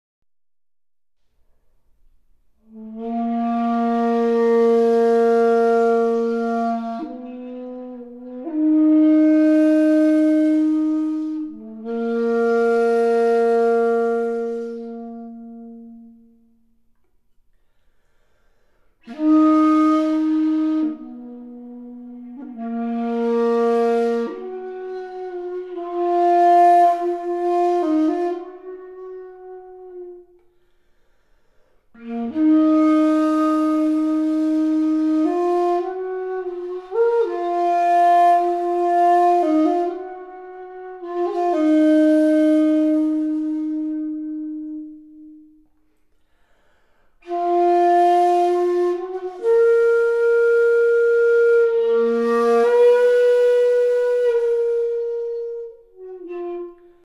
zen shakuhachi